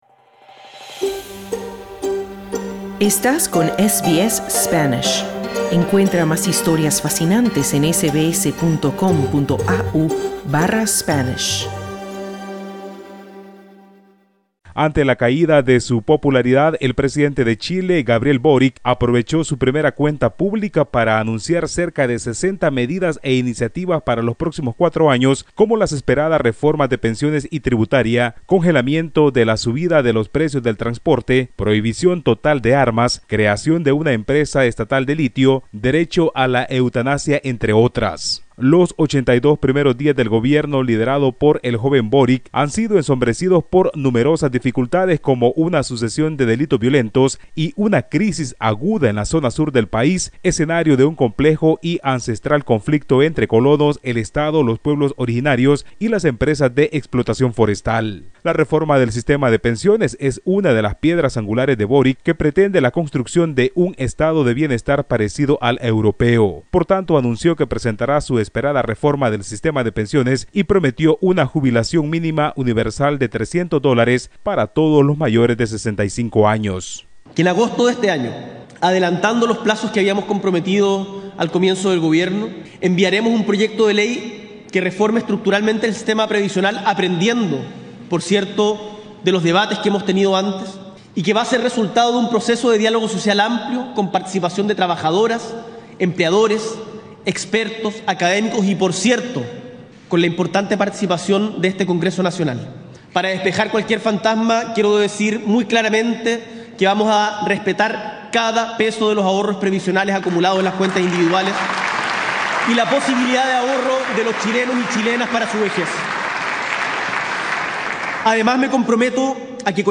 Escucha el informe del corresponsal de SBS Spanish en Latinoamérica